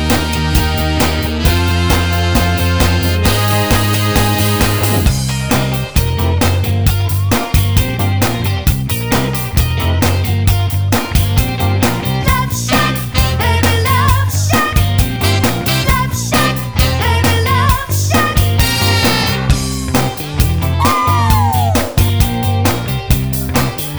Duets